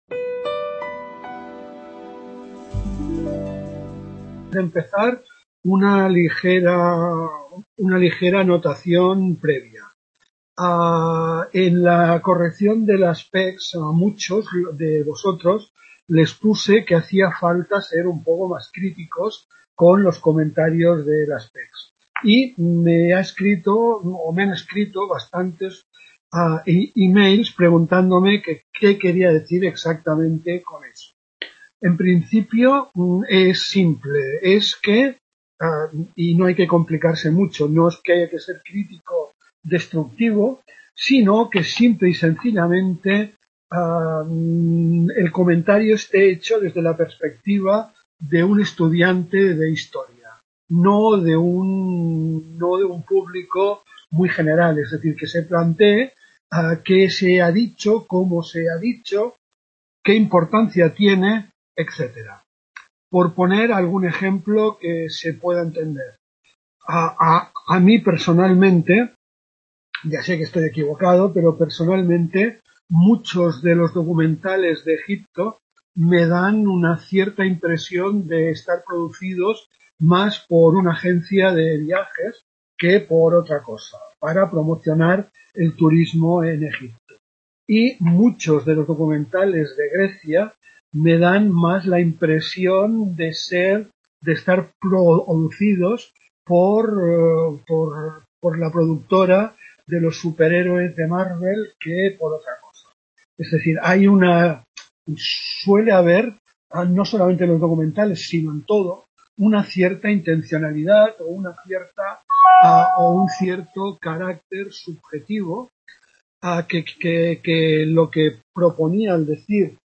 Tutoria